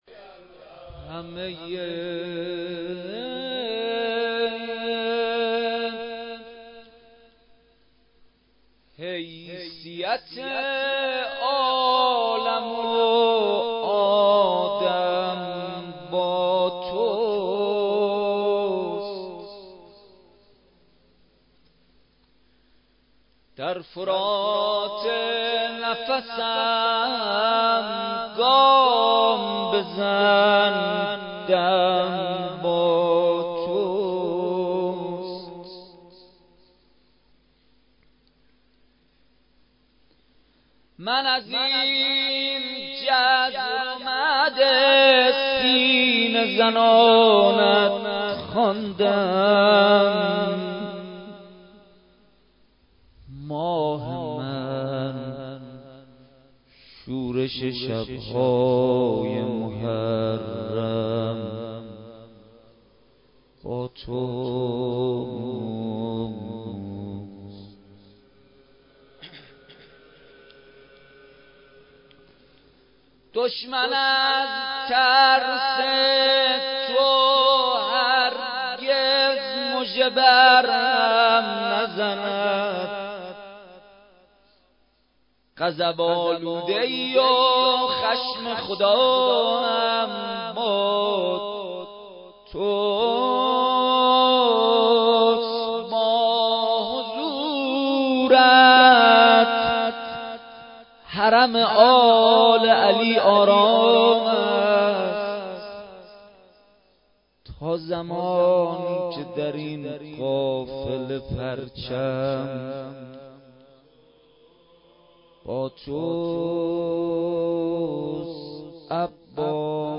شب هشتم ماه رمضان با مداحی کربلایی محمدحسین پویانفر در ولنجک – بلوار دانشجو – کهف الشهداء برگزار گردید.
بخش اول :مناجات بخش دوم:روضه لینک کپی شد گزارش خطا پسندها 0 اشتراک گذاری فیسبوک سروش واتس‌اپ لینکدین توییتر تلگرام اشتراک گذاری فیسبوک سروش واتس‌اپ لینکدین توییتر تلگرام